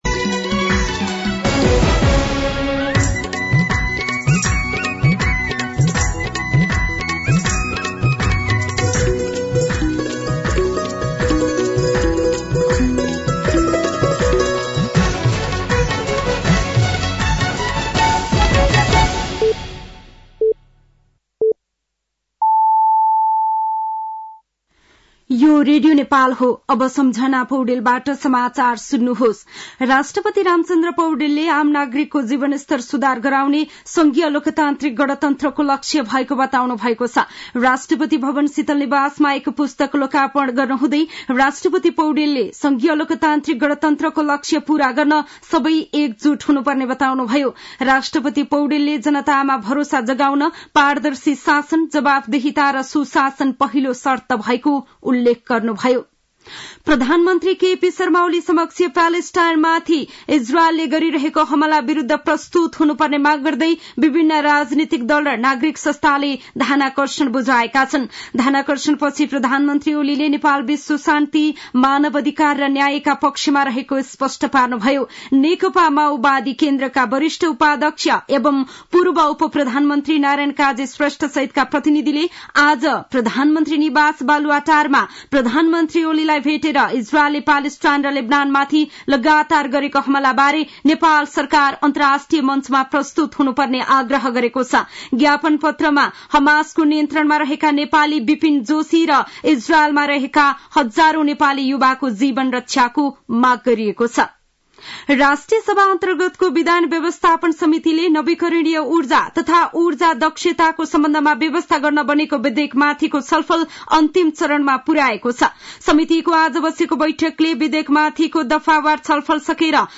साँझ ५ बजेको नेपाली समाचार : ६ मंसिर , २०८१
5-PM-Nepali-News-8-5.mp3